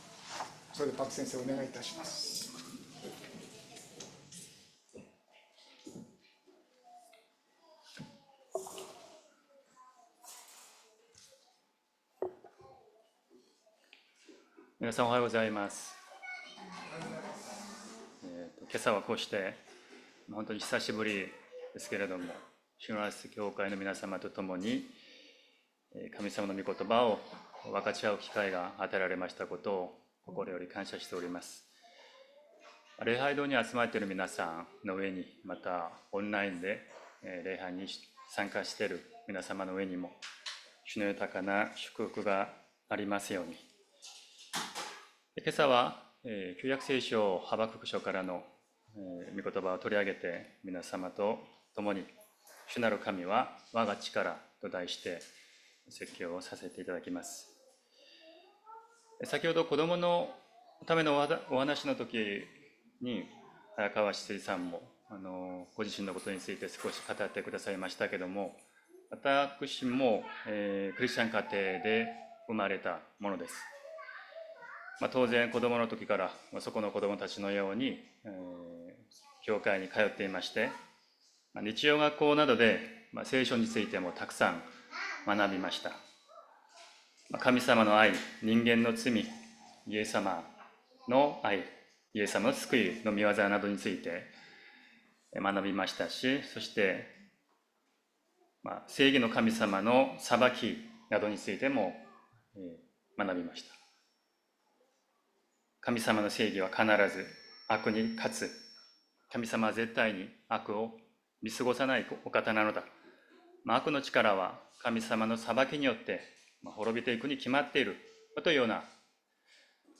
日曜朝の礼拝
筑波みことば） 聖書 ローマの信徒への手紙 1章16節～17節 音声ファイル 礼拝説教を録音した音声ファイルを公開しています。